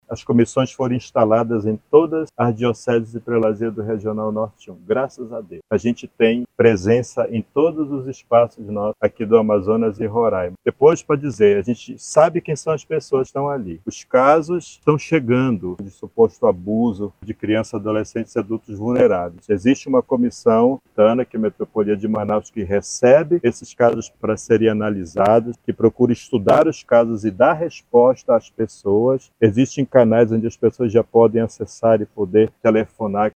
O bispo auxiliar de Manaus e referencial da causa, Dom Hudson Ribeiro, destaca a importância do trabalho conjunto das igrejas locais para proteger as crianças e adolescentes.